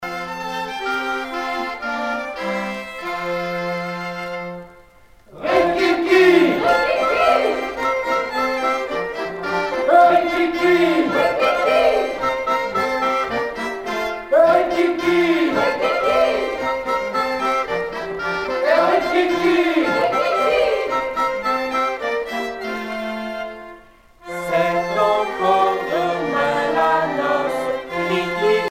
danse : ronde
circonstance : fiançaille, noce
Pièce musicale éditée